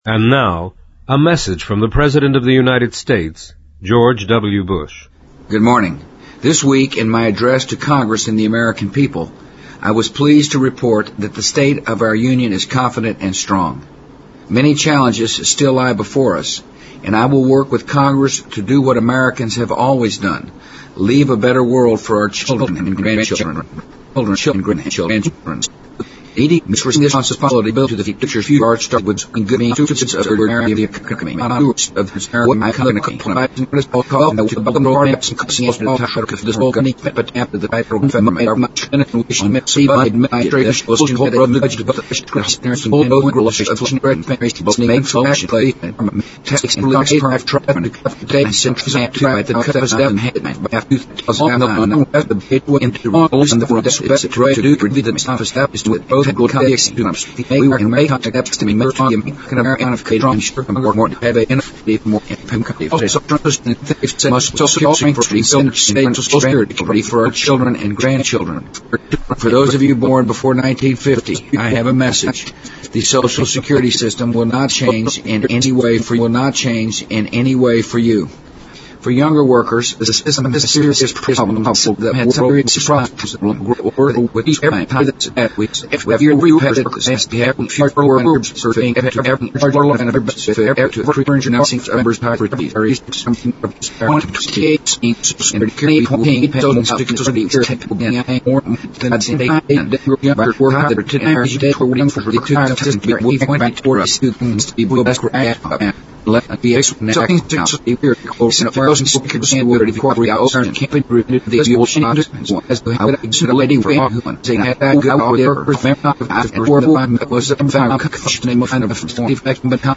President Bush-2005-02-05电台演说 听力文件下载—在线英语听力室